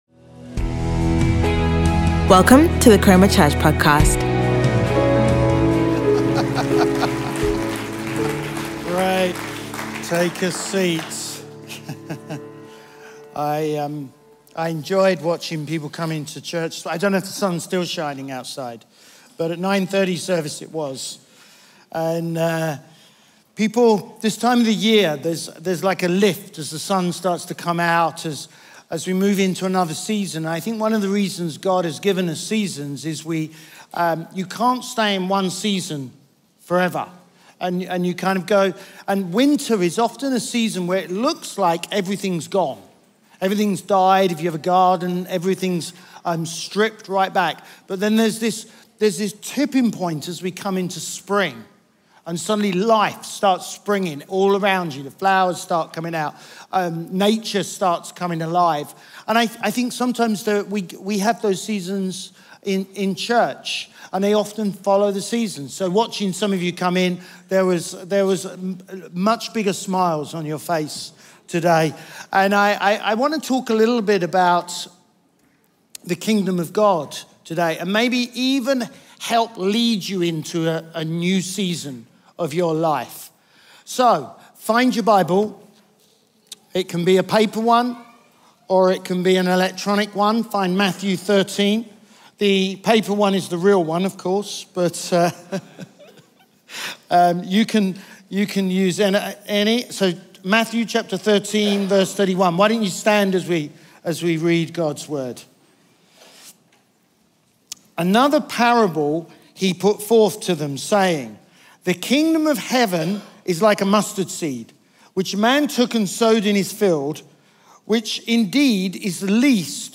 Chroma Church Live Stream
Sunday Sermon Parable Of The Mustard Seed